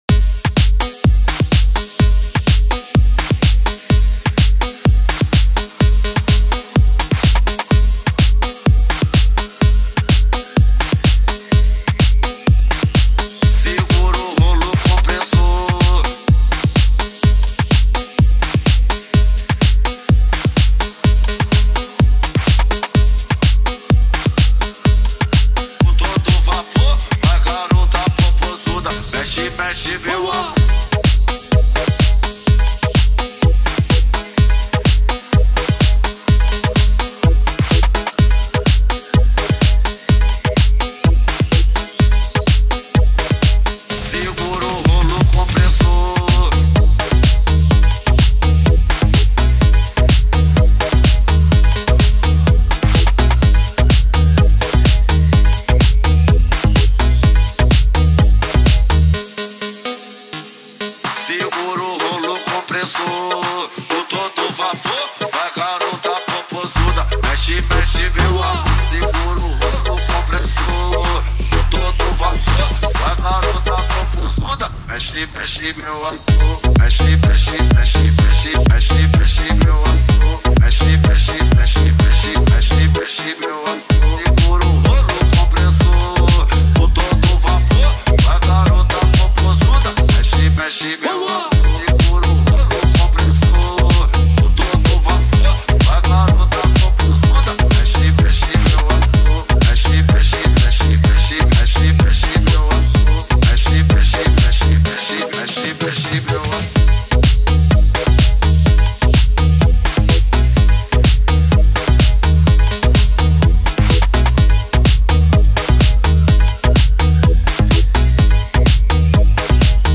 2024-10-16 21:31:41 Gênero: Phonk Views